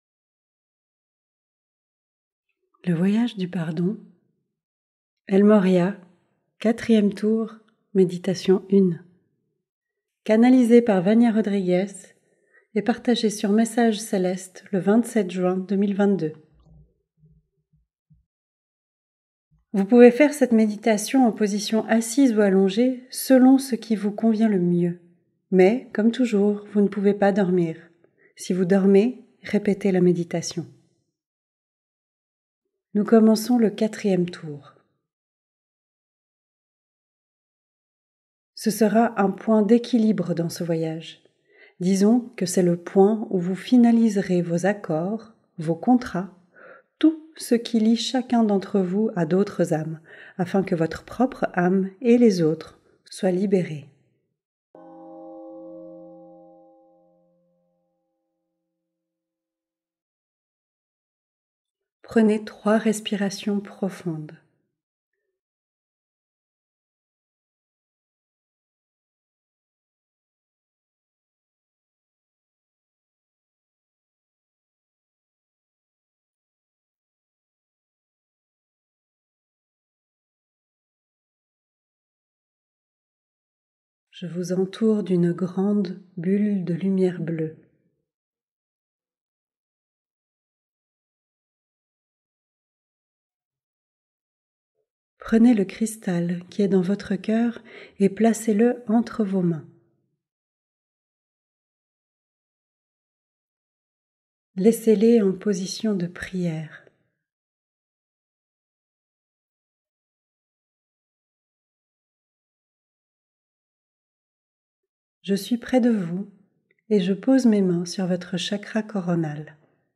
Tour 4 - Méditation 1 - sans_pub